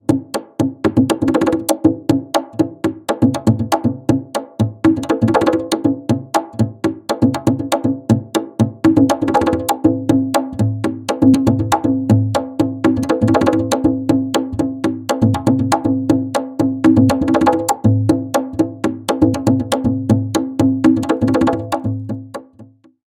Drum Modelling Examples
multi strokes